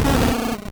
Cri de Magby dans Pokémon Or et Argent.